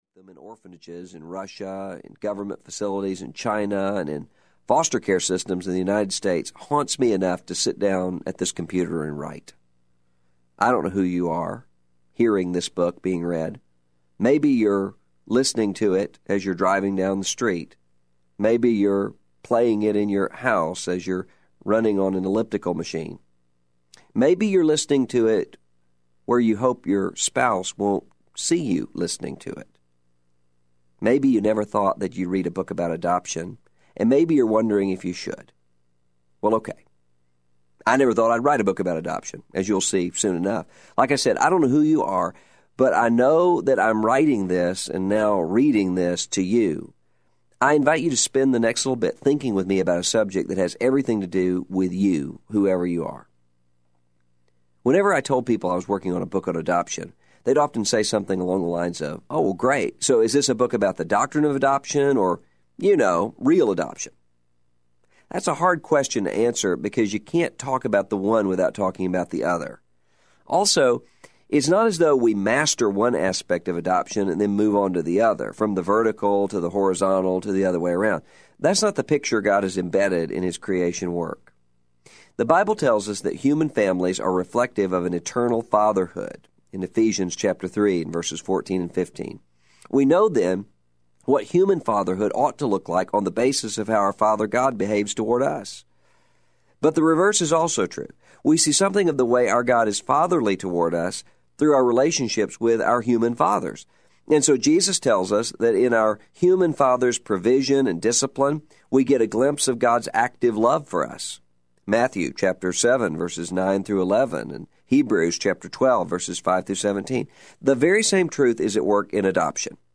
Adopted for Life Audiobook
7 Hrs. – Unabridged